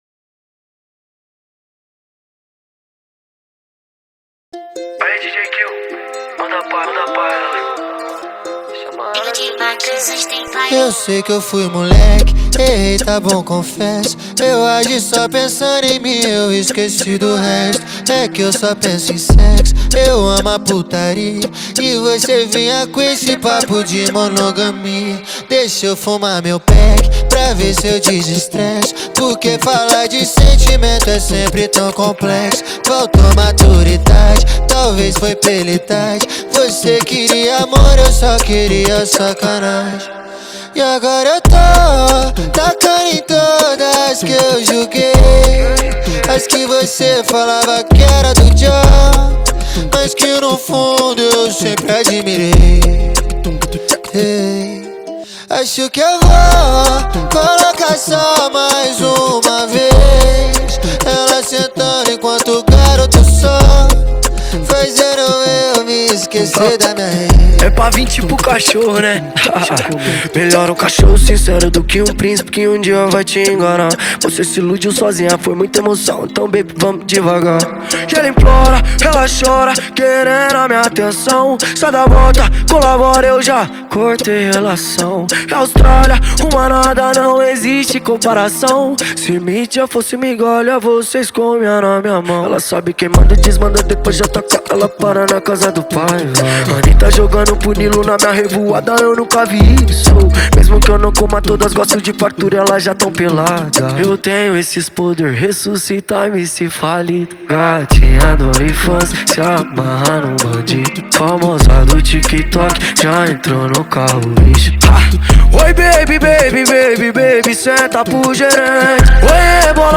2025-01-07 05:05:20 Gênero: Funk Views